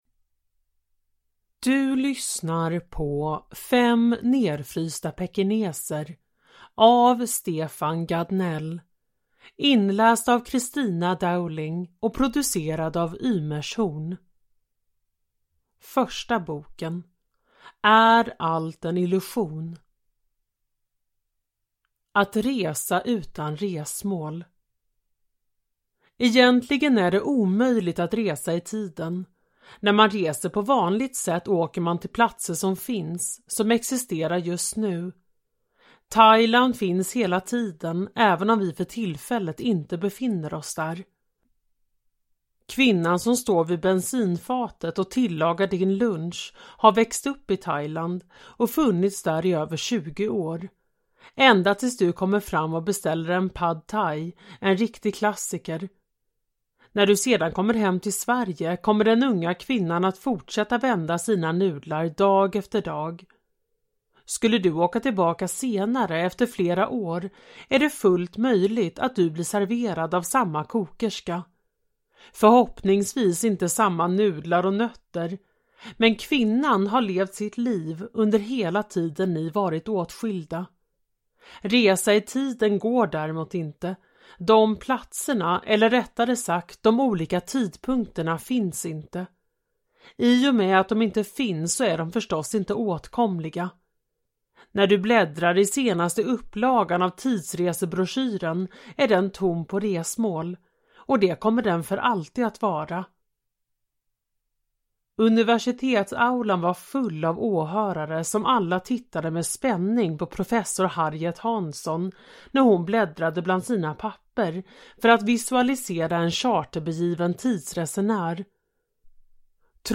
Fem Nedfrysta Pekineser / Ljudbok